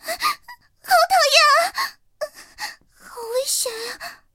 T-127中破语音.OGG